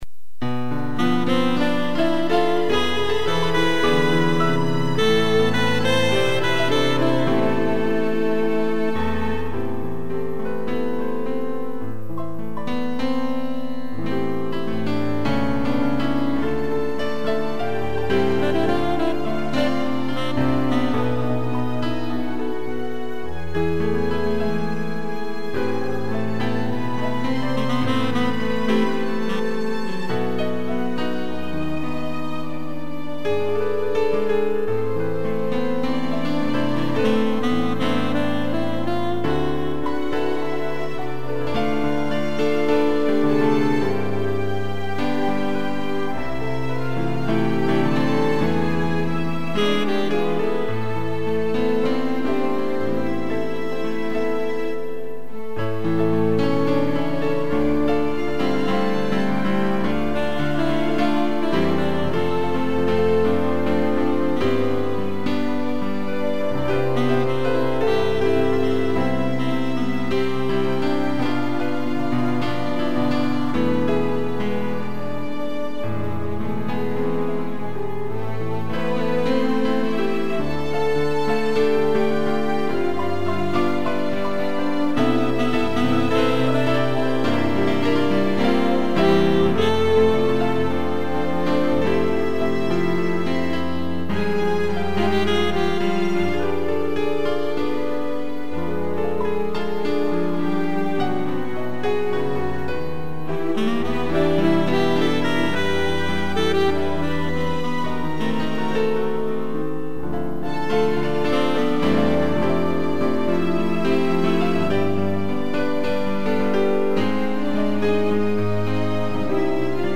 2 pianos, cello, violino e sax
(instrumental)